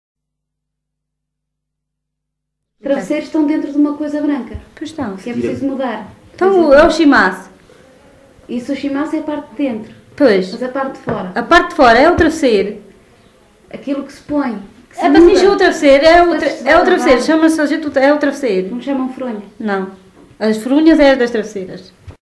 LocalidadeAlpalhão (Nisa, Portalegre)